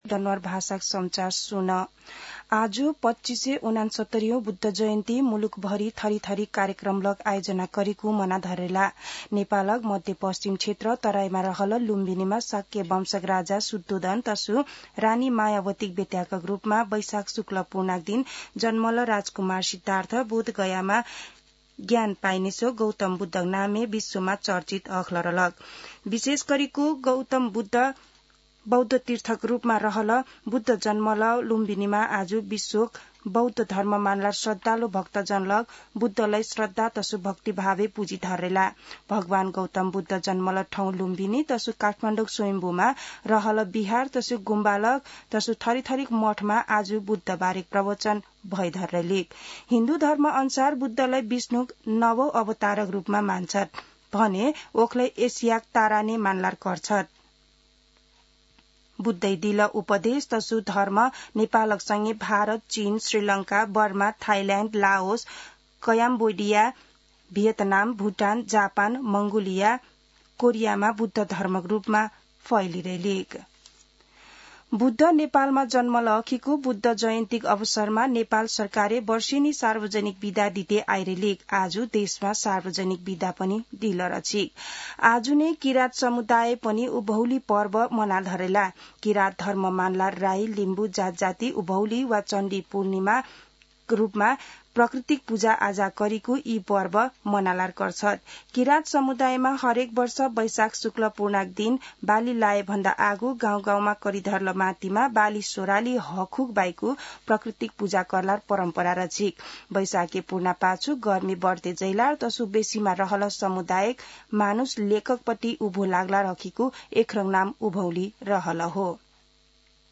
दनुवार भाषामा समाचार : २९ वैशाख , २०८२
Danuwar-News-29.mp3